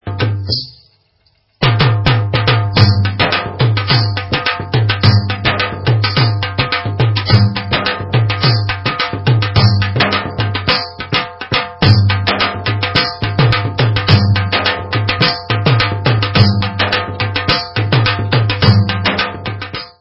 Ryhthm Pattern 6